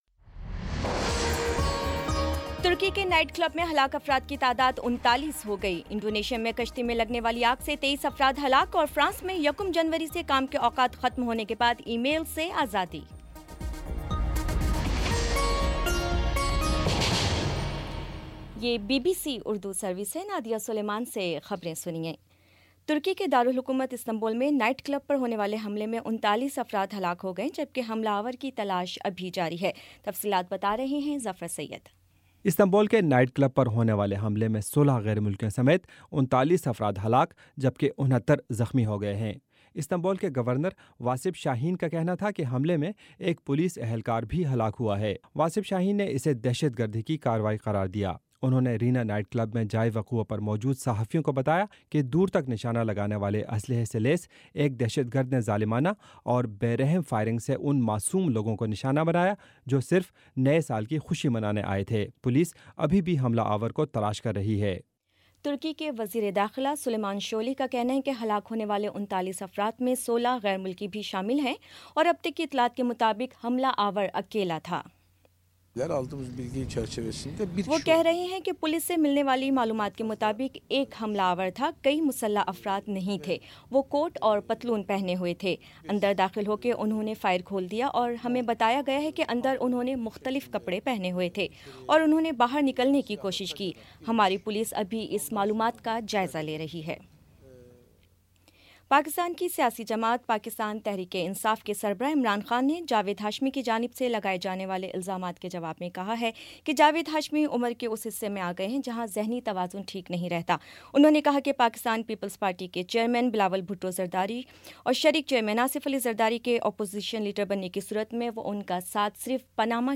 جنوری 01 : شام پانچ بجے کا نیوز بُلیٹن